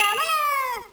bomb.ogg